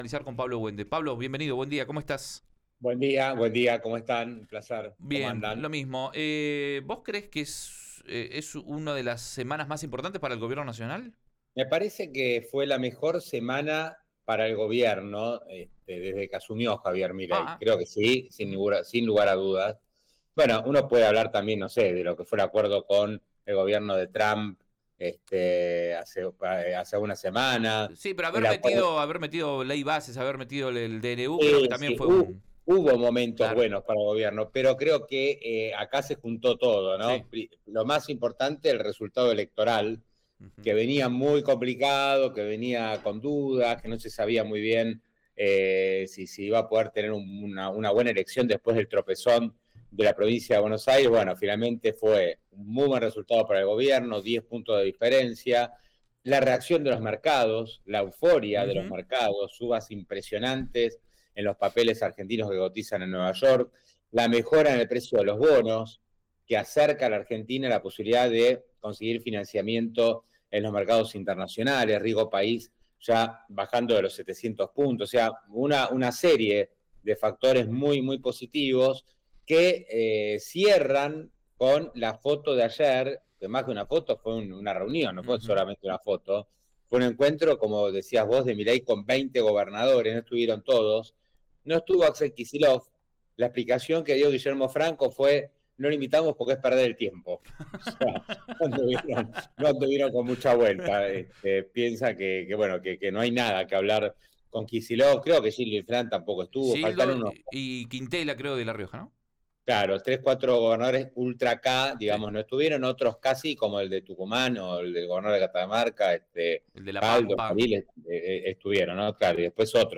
En su columna en Río Negro Radio